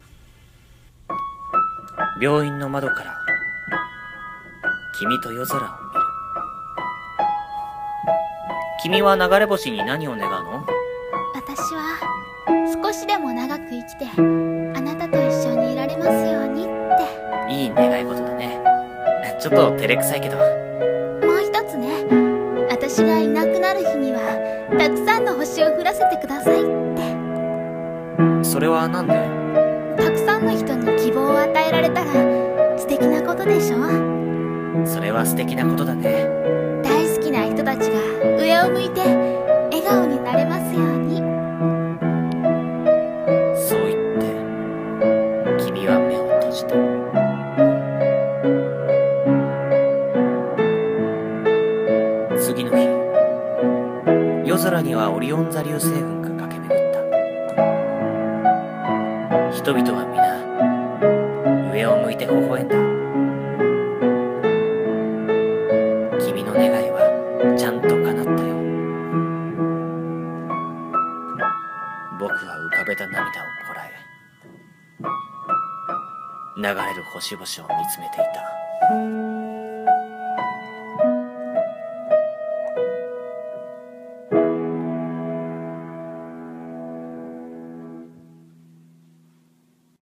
掛け合い